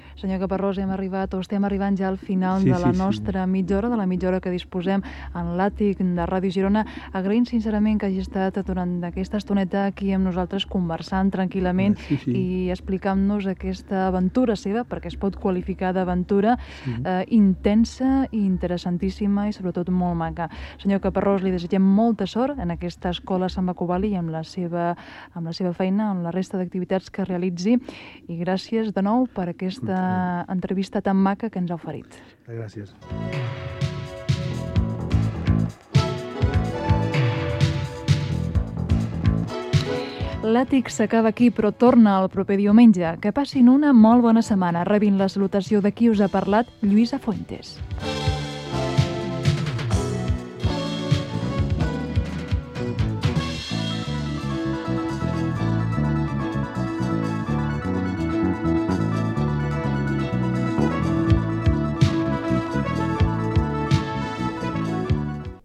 Comiat del programa.
Entreteniment